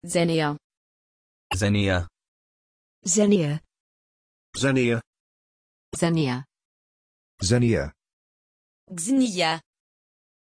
Prononciation de Xeniya
pronunciation-xeniya-en.mp3